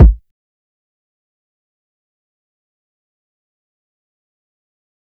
Flick Kick.wav